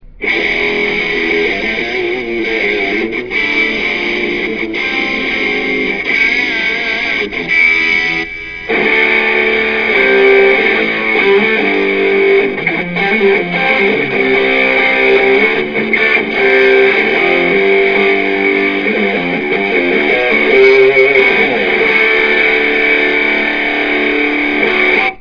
These were recorded off the Line 6 Sampler CD. If you want to hear the full capabilites of the amp I definetly suggest that you inquire Line 6 about one of these CD's. These samples were recorded directly on to my computer so the definition and clarity isn't perfect.
This is the Mesa Boogie Rectifier Model. If you like distortion you will like this.
dualrectifer.ra